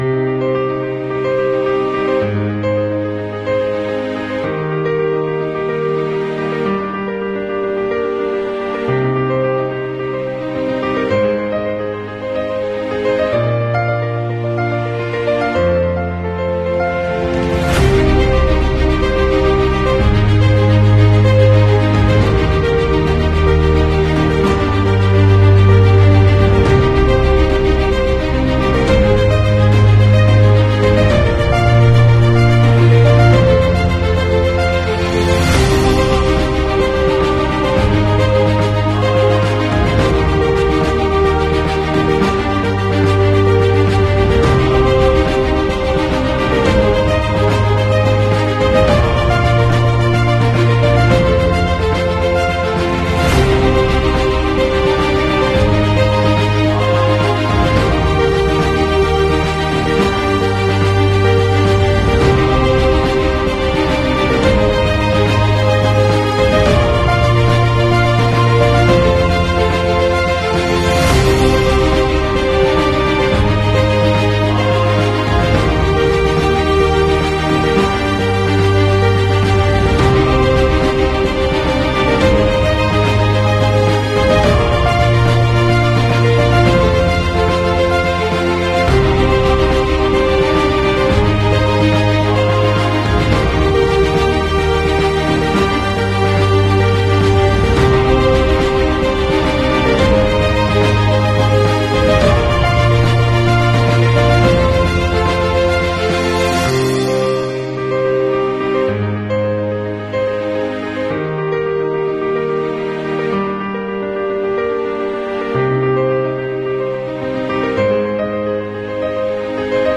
Philippine Airlines ✈🛬🇵🇭 PAL 212 Fleet: Airbus A330-343 Registration: RP-C8780 Route: Sydney (SYD) 🇦🇺 ➡ Manila (MNL) 🇵🇭 Average flight time: 07:40 Barometric altitude: 7,300- ft.